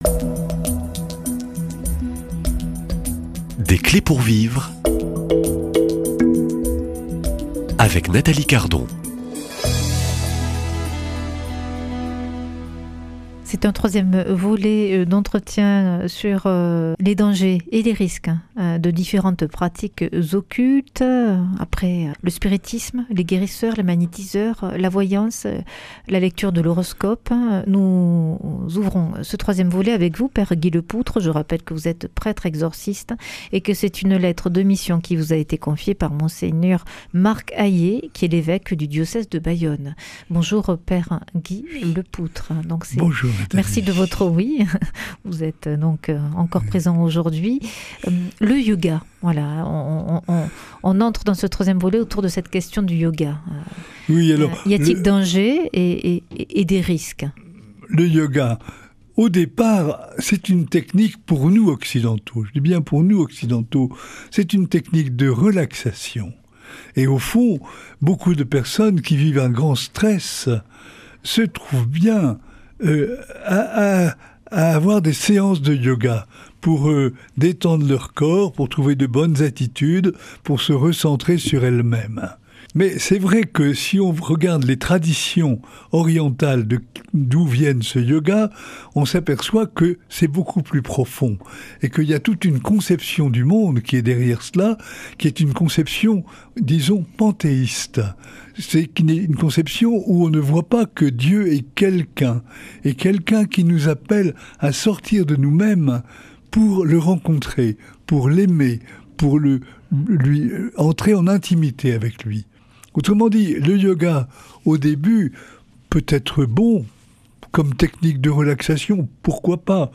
Une émission présentée par
Journaliste